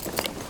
tac_gear_9.ogg